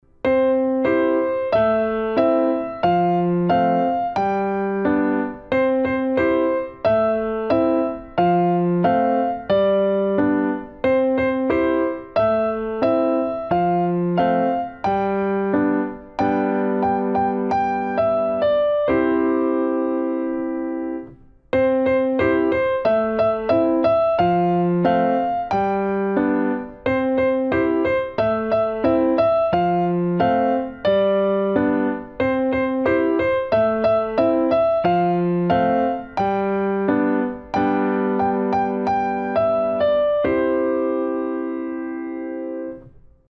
Tři citronky na klavír - videolekce a noty pro začátečníky
5-Tři-citrónky-obě-ruce-s-akordy-půleně-90.mp3